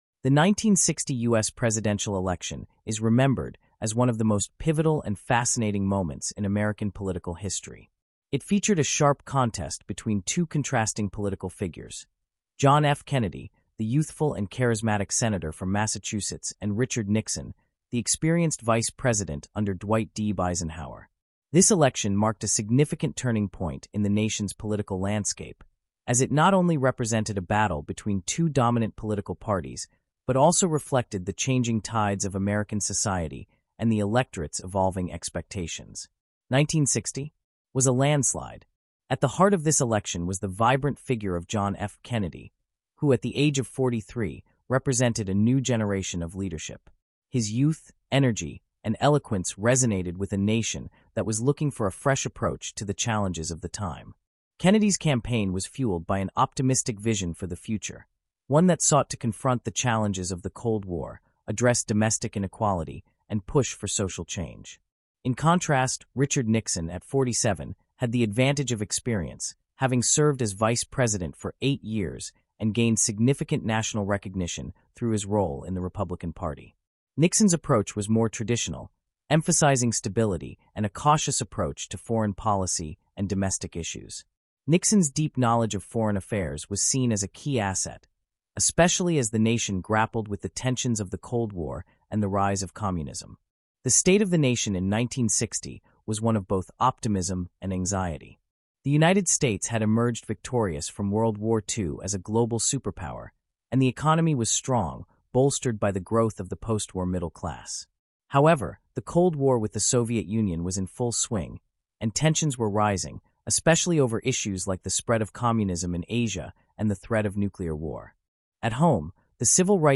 Disclosure: This podcast includes content generated using an AI voice model. While efforts were made to ensure accuracy and clarity, some voices may not represent real individuals.